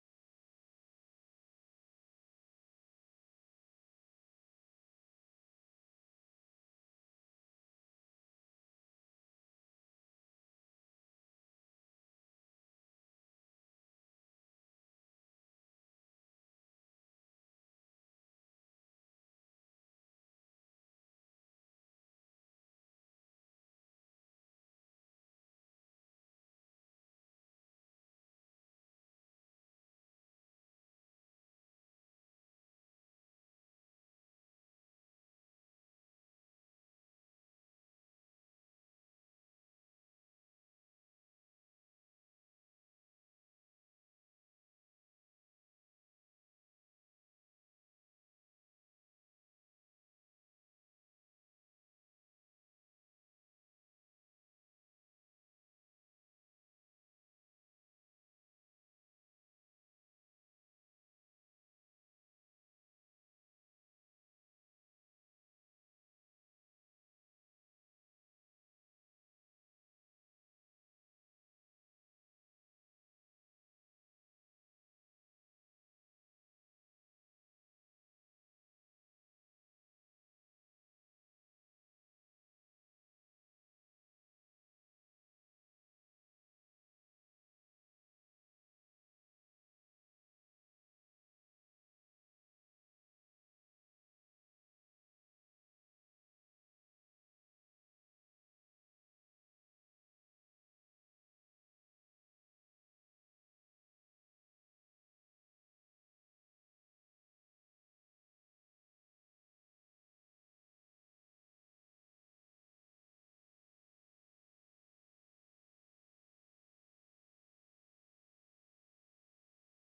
Passage: Hebrews 10:22 Service Type: Sunday PM « September 22